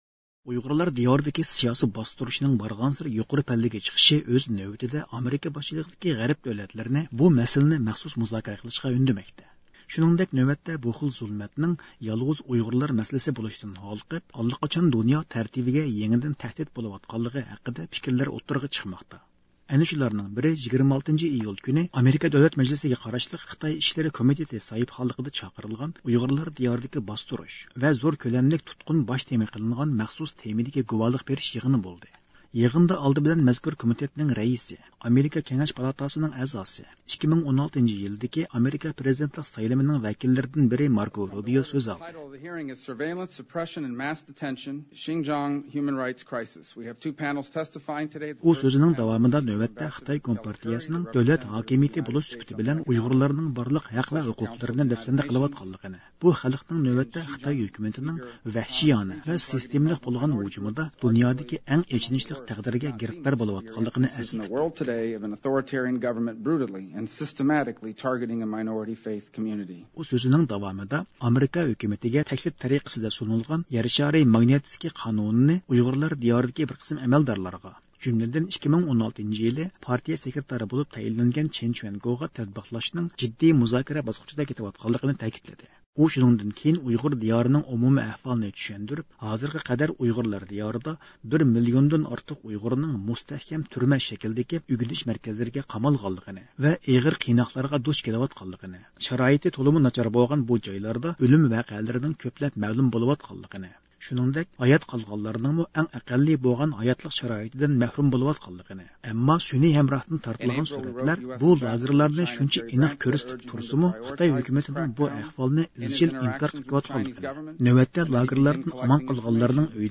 يىغىندا ئالدى بىلەن مەزكۇر كومىتېتنىڭ رەئىسى، ئامېرىكا كېڭەش پالاتاسىنىڭ ئەزاسى، 2016-يىلىدىكى ئامېرىكا پرېزىدېنتلىق سايلىمىنىڭ كاندىداتلىرىدىن بىرى ماركو رۇبيۇ سۆز ئالدى.
ئۇيغۇرلار دىيارىدىكى سىياسىي ۋەزىيەت ھەققىدە ئامېرىكا دۆلەت مەجلىسىدە ئۆتكۈزۈلگەن گۇۋاھلىق بېرىش يىغىنىنىڭ بىرىنچى باسقۇچىدا گۇۋاھچىلار سۆز قىلماقتا،